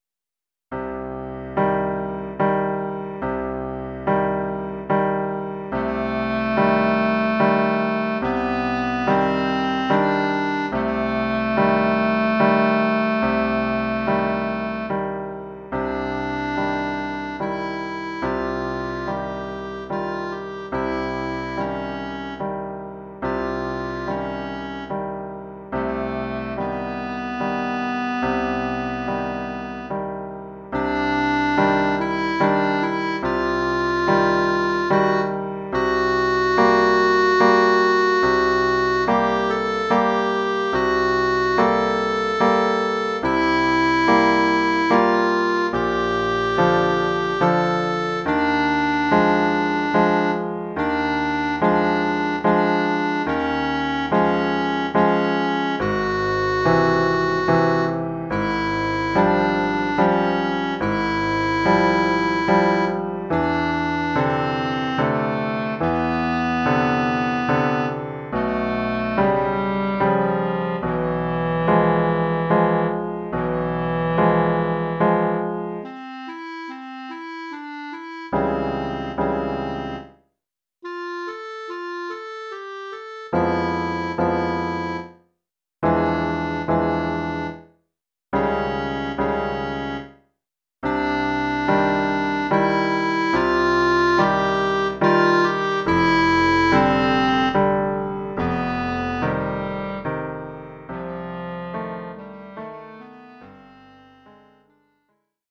Genre musical : Classique
Formule instrumentale : Clarinette et piano
Oeuvre pour clarinette et piano.